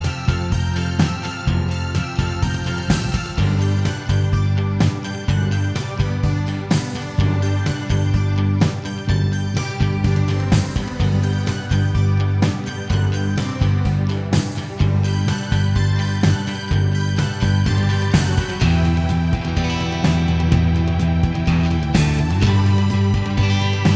Minus Lead Guitar Pop (1970s) 3:45 Buy £1.50